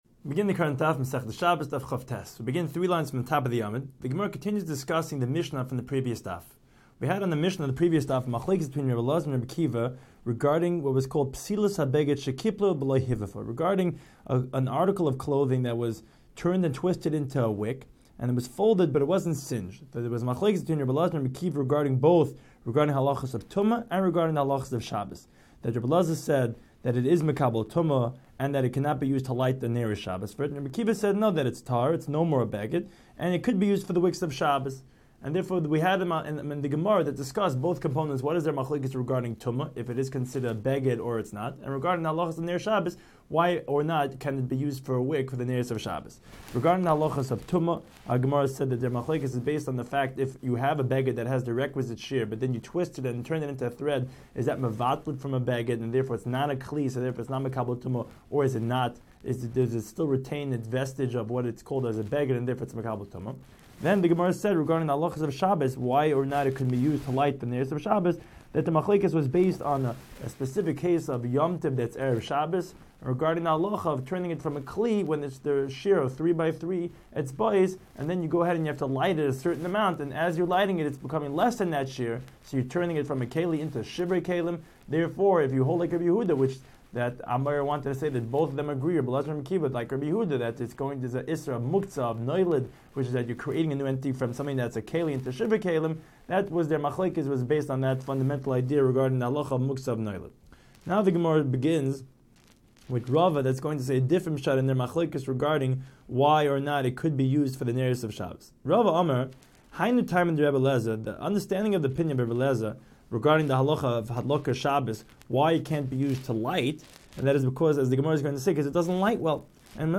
Daf Hachaim Shiur for Shabbos 29